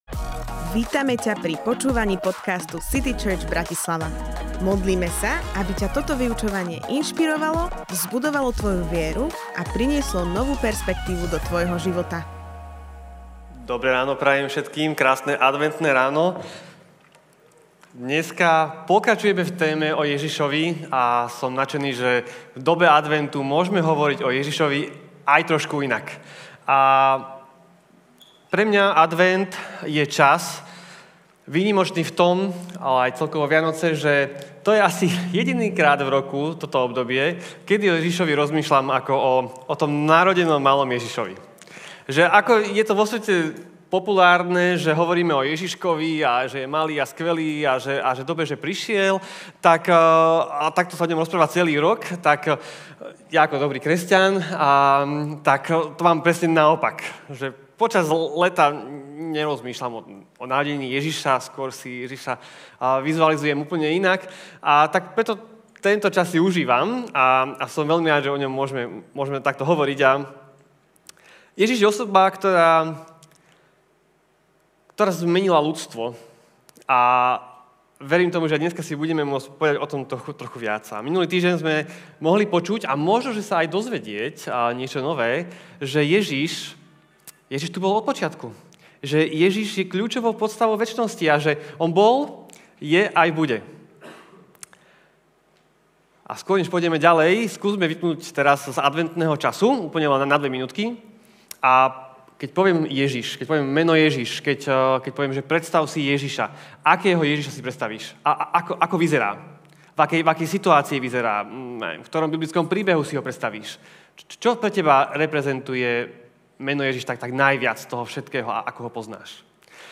Kľúčová postava ľudstva Kázeň týždňa Zo série kázní